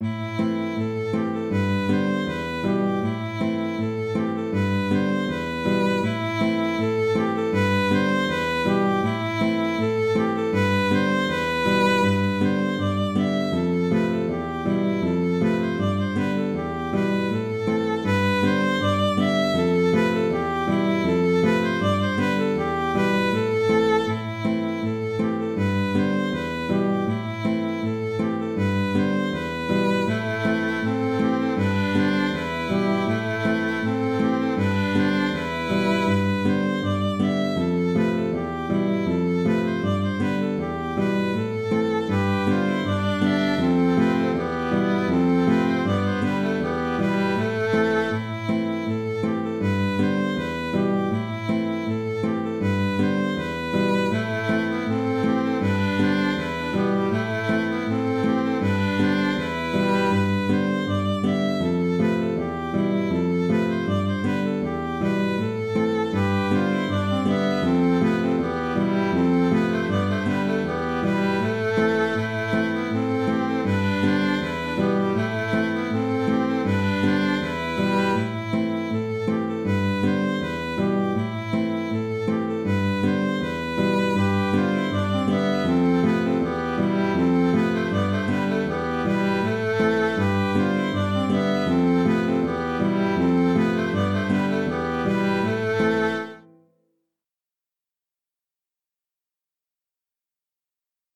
C’est un chant de Noël dont je ne connais pas les paroles ni l’origine.
Le premier contre-chant est ultra simple. Le second contrechant est quasiment le même que le premier, avec quelques notes en plus.
Musique bretonne
Kas a barh